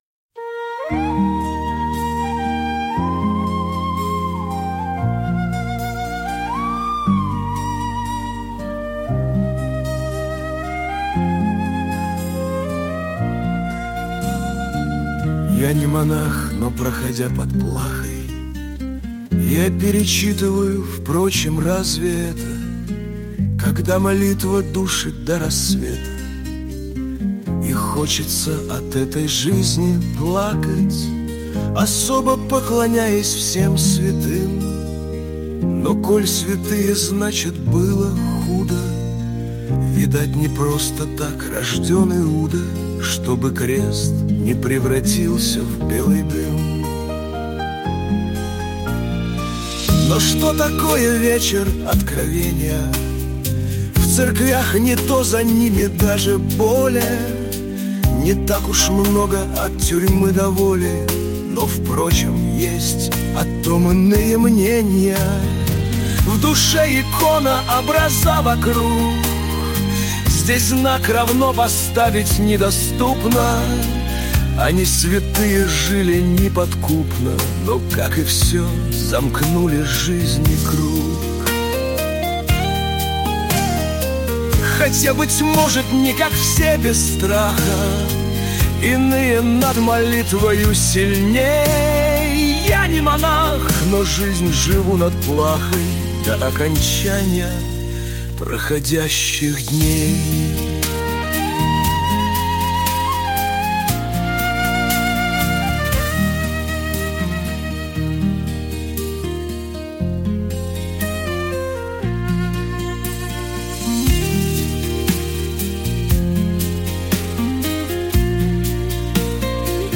Прямо как классическое произведение.